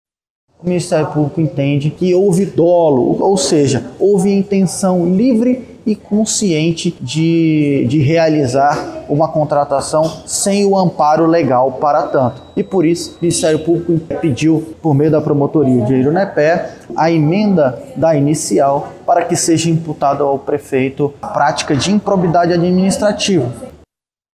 Uma Ação Civil Pública foi ajuizada no último mês por improbidade administrativa, como relata Caio Lúcio Fenalon, titular da Promotoria de Justiça de Eirunepé.
Sonora-Caio-Lucio-Fenalon-titular-da-Promotoria-de-Justica-de-Eirunepe.mp3